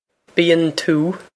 bíonn tú bee-on too
bee-on too
This is an approximate phonetic pronunciation of the phrase.